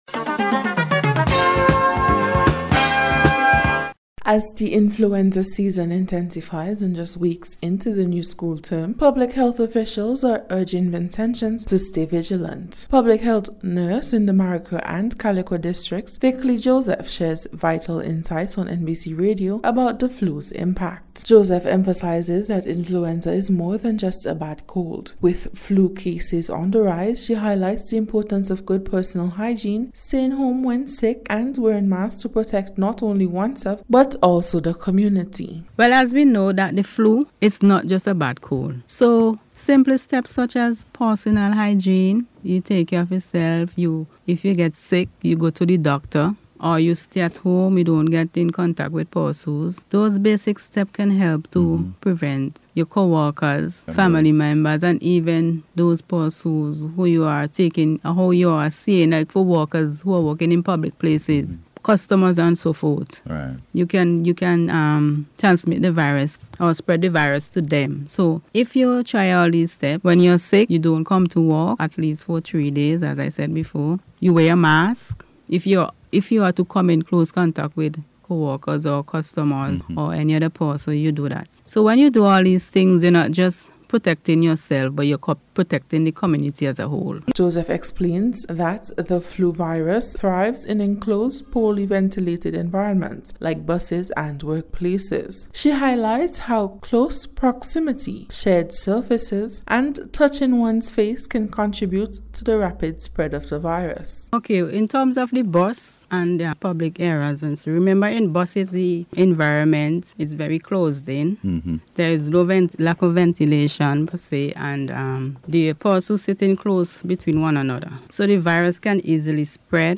NBC’s Special Report- Friday 23rd January,2026